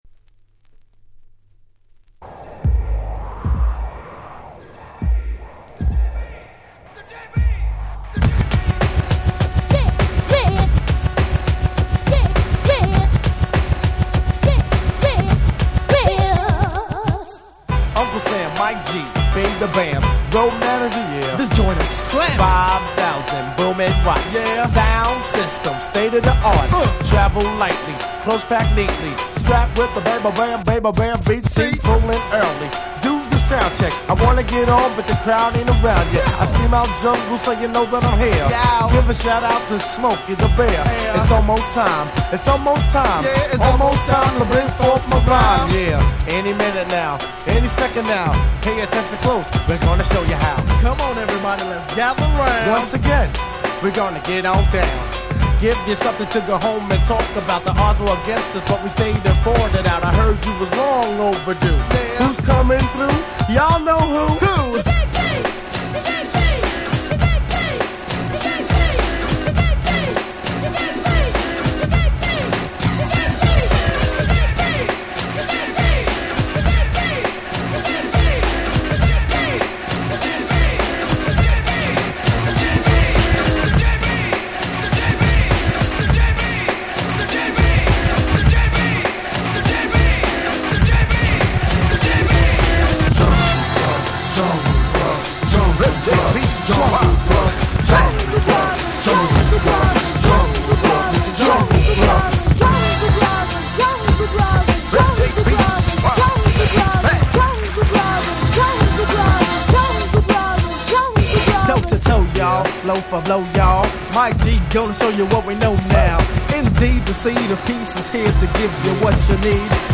90' Smash Hit Hip Hop !!